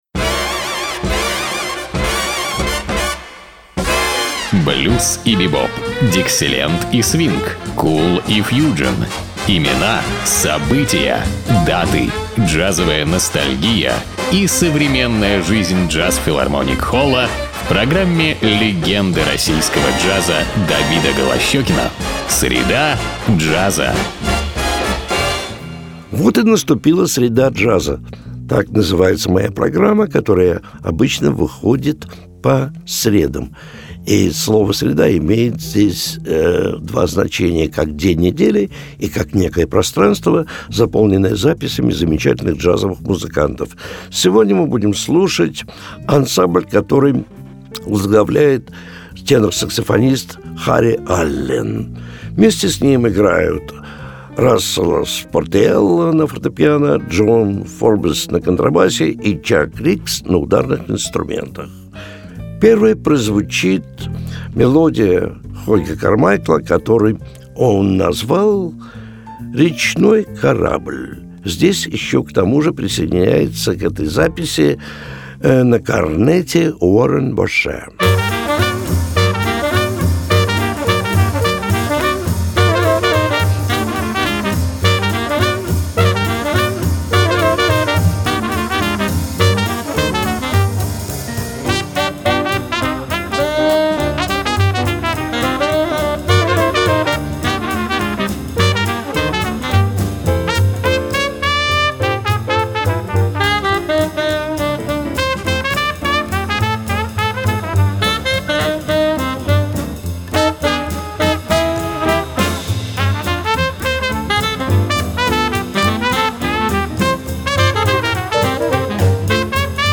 тенор-саксофон
фортепьяно
контрабас
ударные
корнет